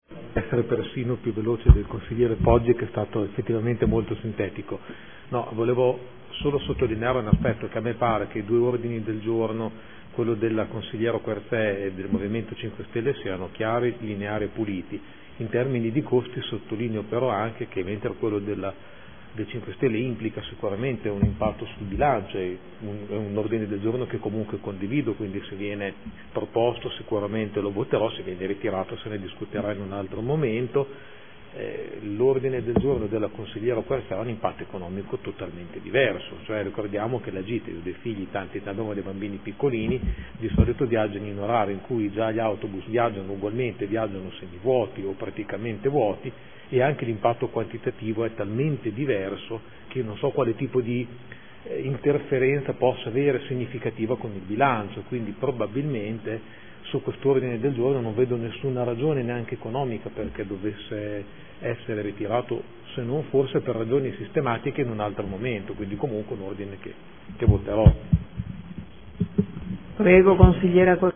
Giuseppe Pellacani — Sito Audio Consiglio Comunale
Seduta del 13/11/2014 Dibattito. Ordini del giorno trasporto scolastico.